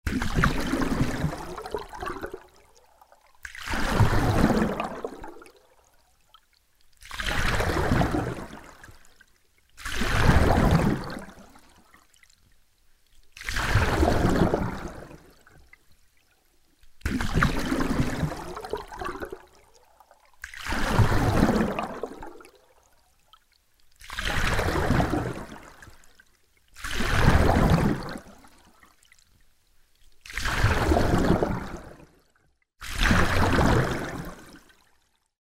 Звуки весла
На этой странице собраны звуки весла: плеск воды, ритмичные гребки и другие умиротворяющие аудиоэффекты.